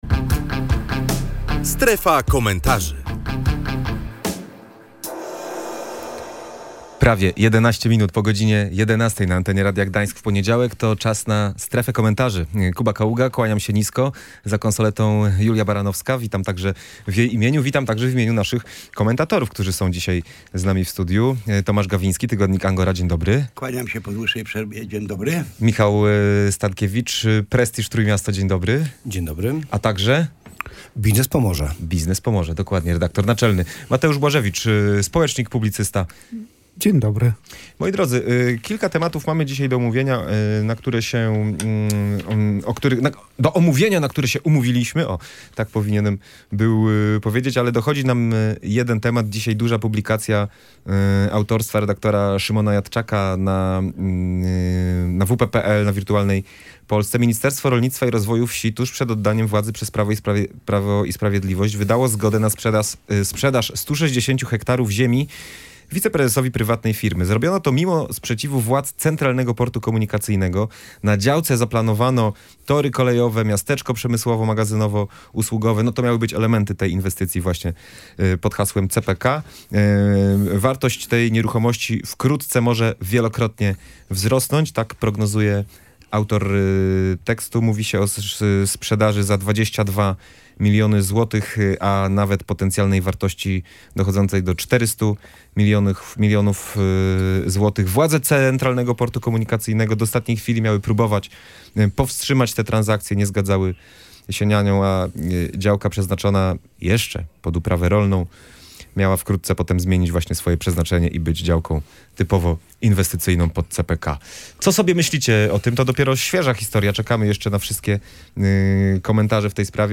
Kontrowersyjna sprzedaż działki pod CPK. Publicyści komentują